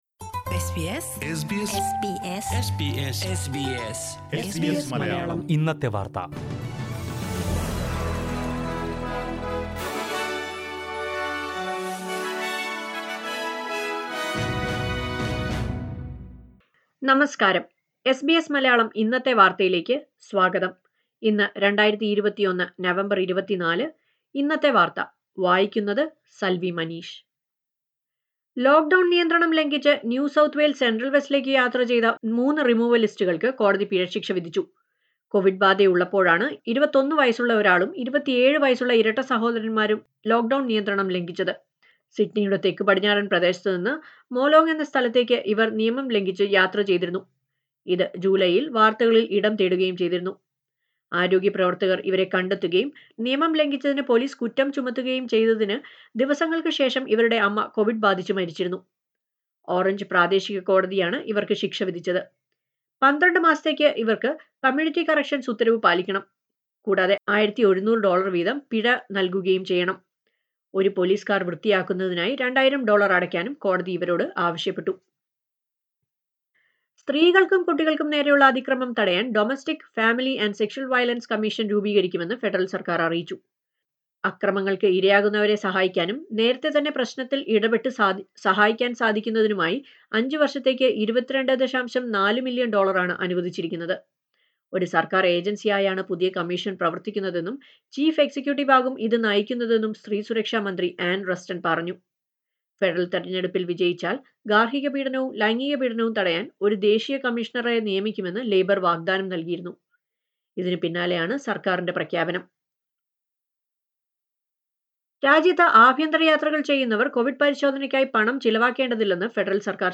SBS Malayalam Today's News: November 24, 2021